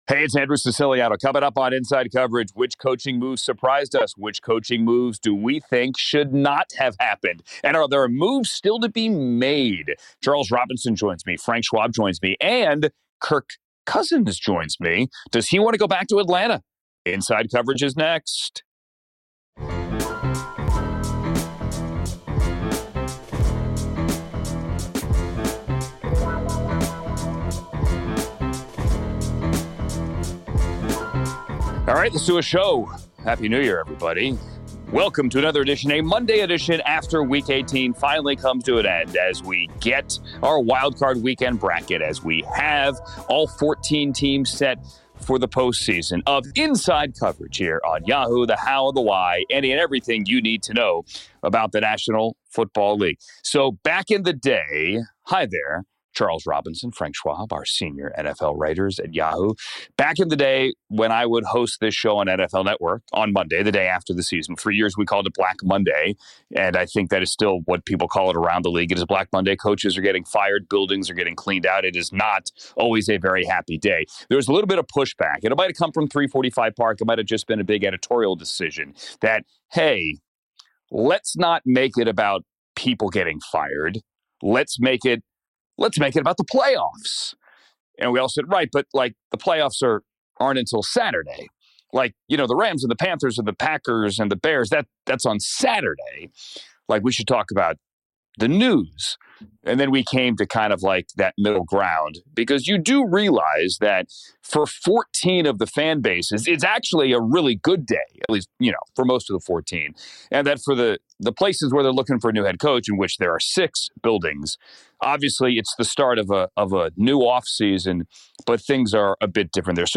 1 Tamar Braxton on Fat Joe's APOLOGY for Toni-Birdman rumor, Cash Money Verzuz, reality TV 1:00:05 Play Pause 7d ago 1:00:05 Play Pause Play later Play later Lists Like Liked 1:00:05 Fat Joe and Jadakiss are joined by R&B royalty Tamar Braxton.